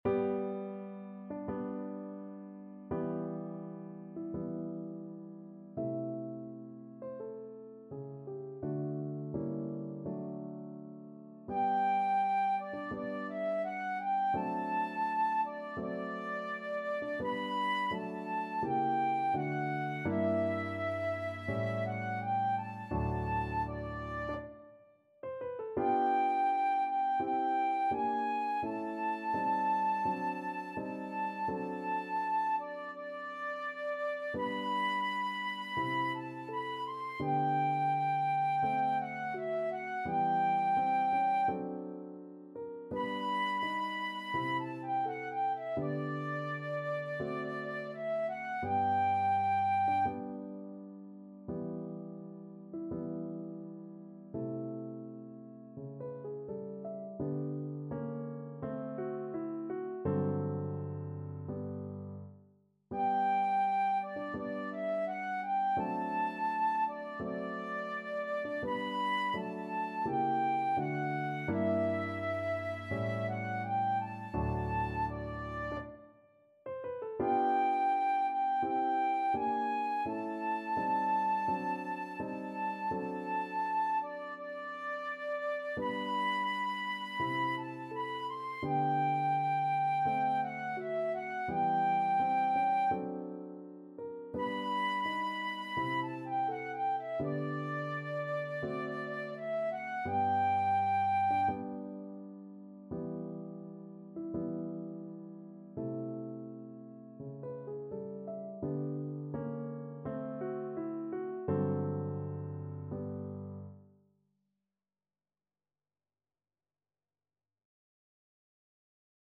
Flute
2/4 (View more 2/4 Music)
G major (Sounding Pitch) (View more G major Music for Flute )
~ = 42 Sehr langsam
Classical (View more Classical Flute Music)